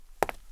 FueraDeEscala/Assets/Game Kit Controller/Sounds/Foot Steps/Concrete/concretFootstep02.wav at c2edc69e8dab52485dff5d61c4bcdd6ed4548aa3
concretFootstep02.wav